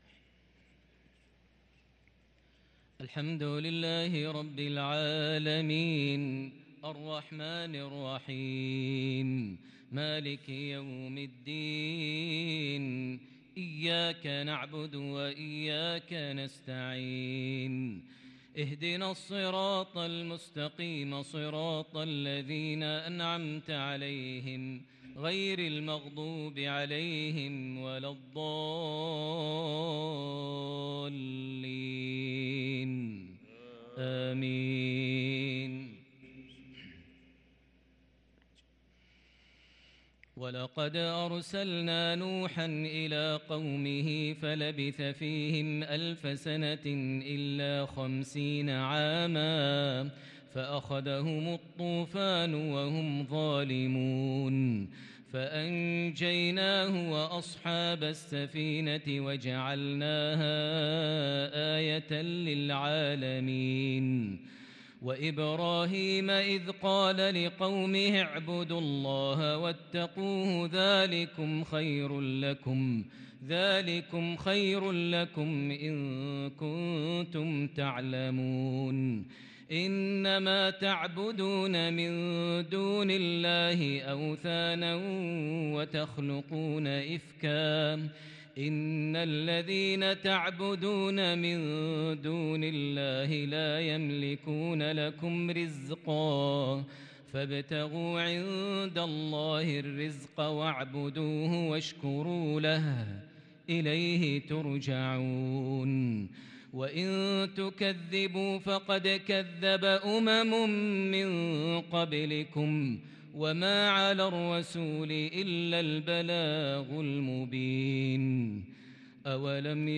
صلاة العشاء للقارئ ماهر المعيقلي 26 ربيع الآخر 1444 هـ
تِلَاوَات الْحَرَمَيْن .